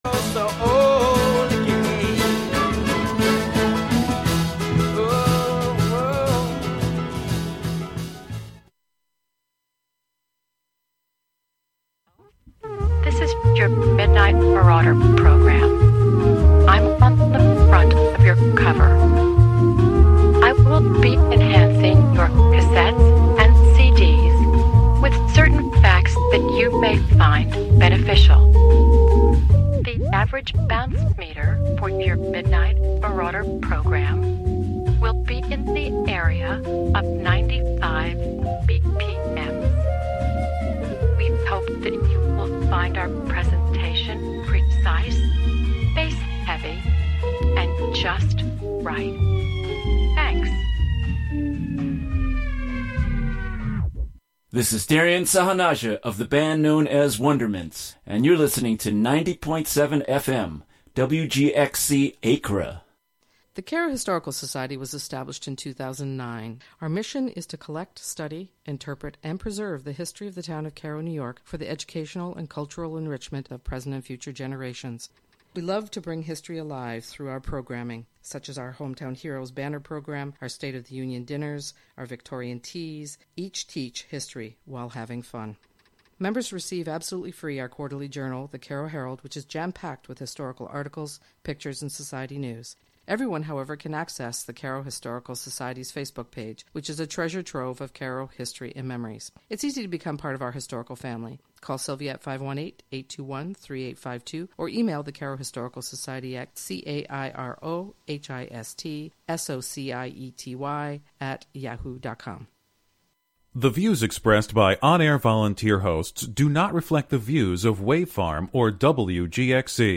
"THERE THERE" began as an investigation into the nature of performance, evolving into a celebration of performance documentation in all its sonic forms: music, spoken word, interview, and archival recordings mixed in with occasional field recordings & found sound.